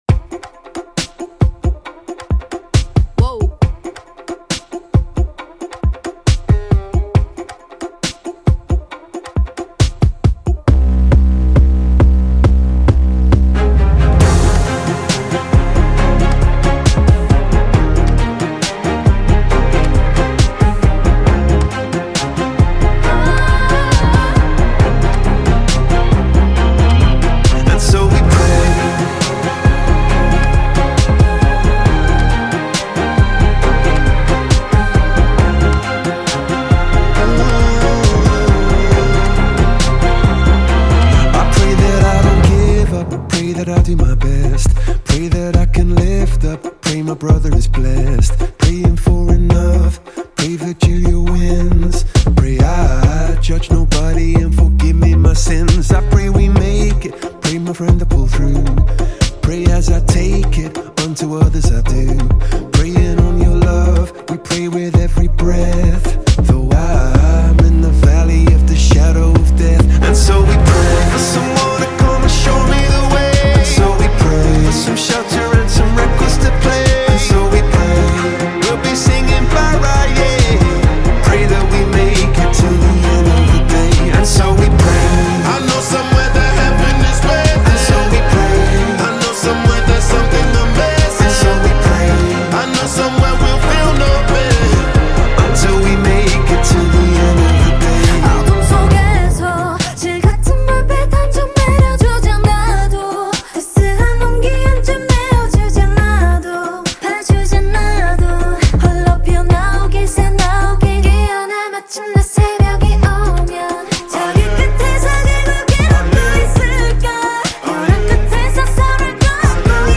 Label Pop